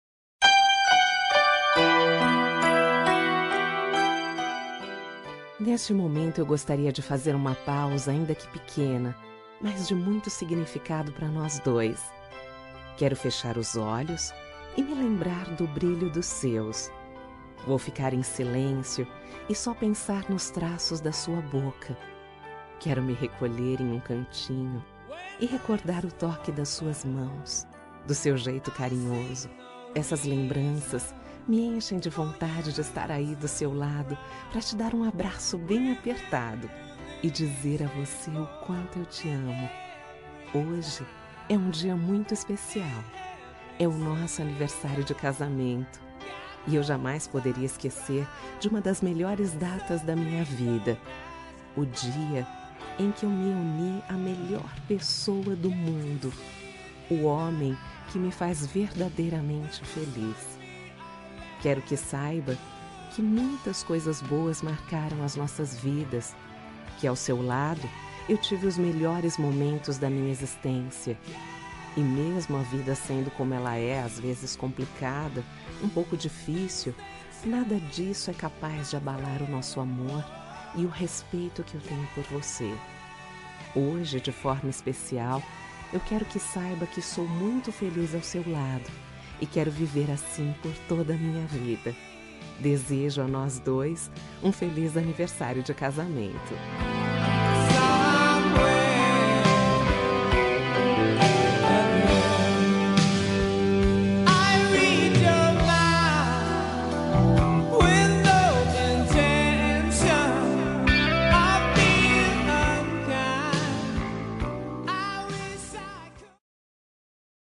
Telemensagem de Aniversário de Casamento Romântico – Voz Feminina – Cód: 5005